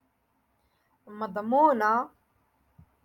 Moroccan Dialect- Rotation Six - Lesson Four